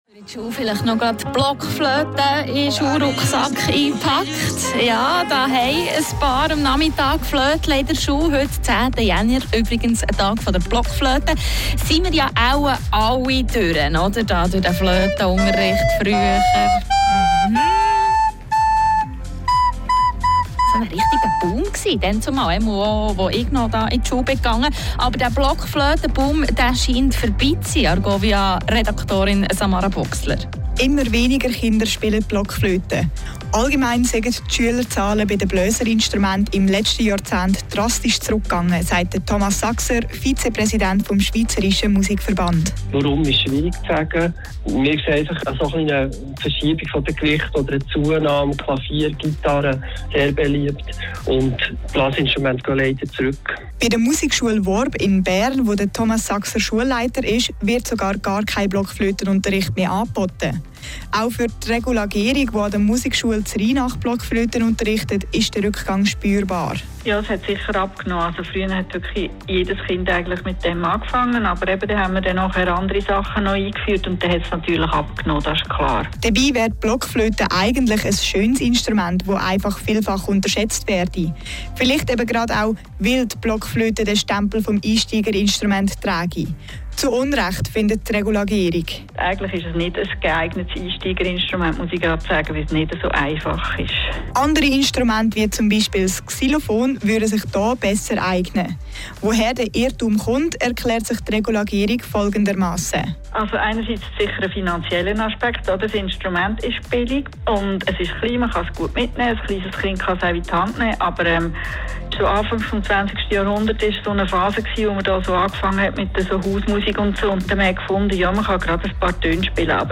Die Moderatorin ging noch davon aus, dass die Blockflöte das ideale Einstiegsintrument ist.